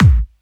VEC3 Bassdrums Trance 62.wav